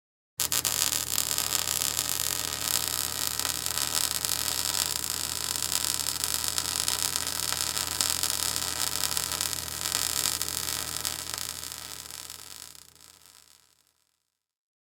Welding Sparks
Welding Sparks is a free sfx sound effect available for download in MP3 format.
Welding Sparks.mp3